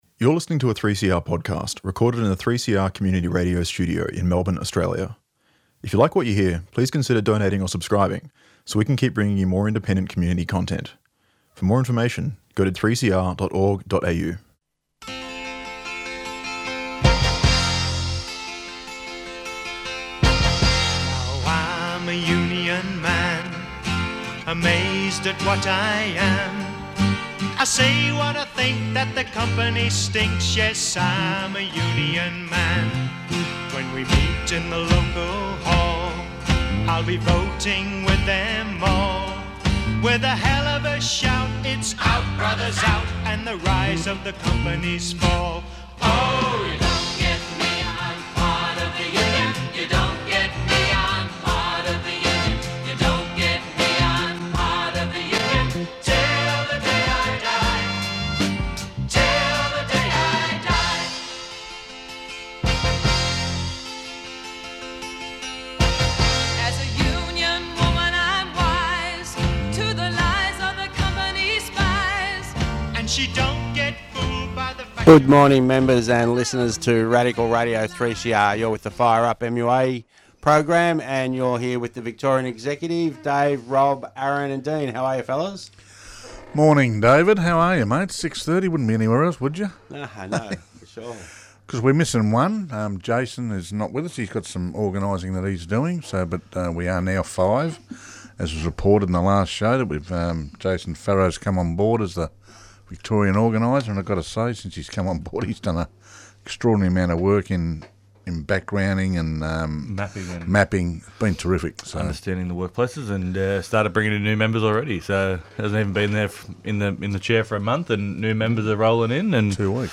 Presenter Members of the MUA